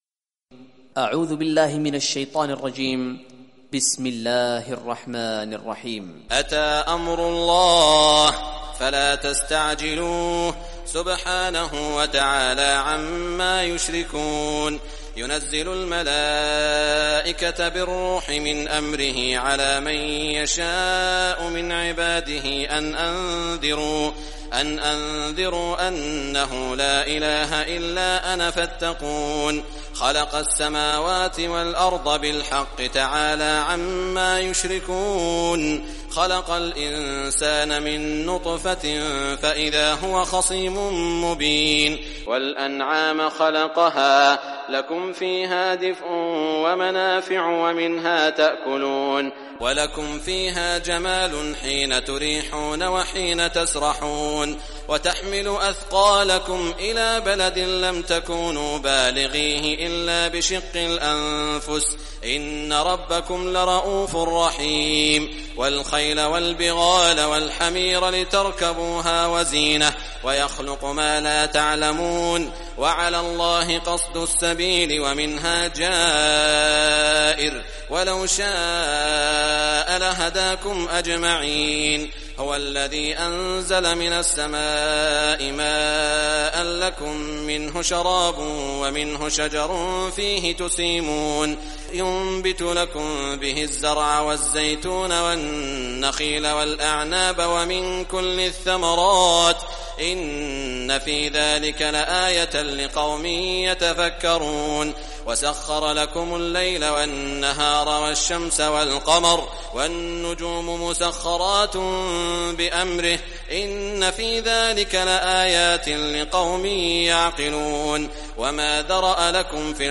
Surah An-Nahl Recitation by Sheikh Saud Al Shuraim
Surah An-Nahl, listen or play online mp3 tilawat / recitation in Arabic in the beautiful voice of Sheikh Saud al Shuraim.